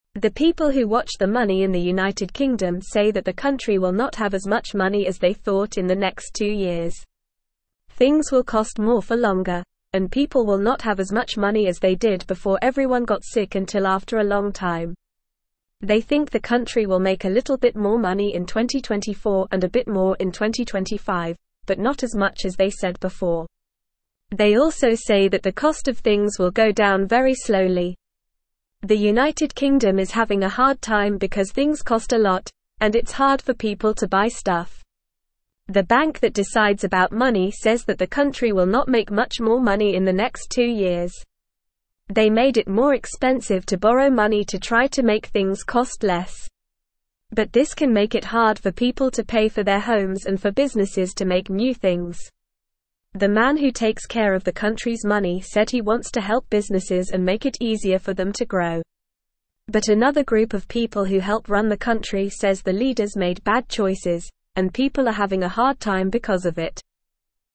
Normal
English-Newsroom-Beginner-NORMAL-Reading-UK-Economy-Faces-Challenges-Less-Money-Higher-Costs.mp3